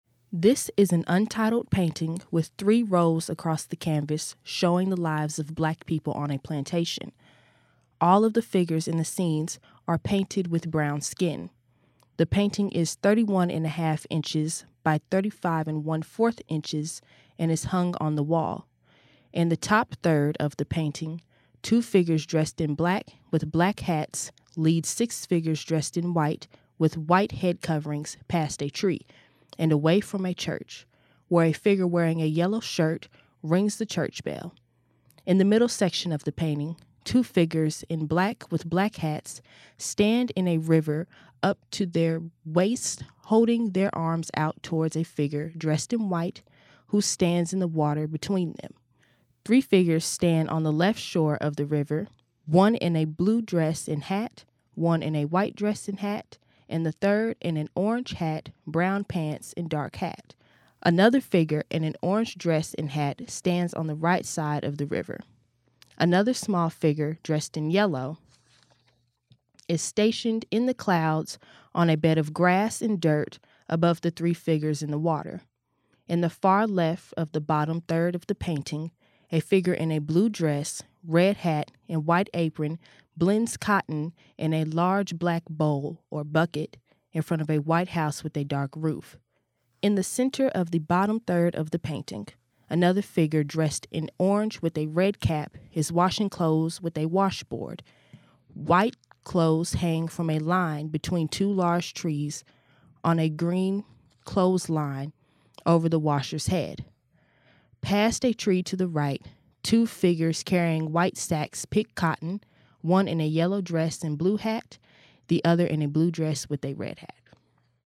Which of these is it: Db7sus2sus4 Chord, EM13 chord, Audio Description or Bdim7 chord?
Audio Description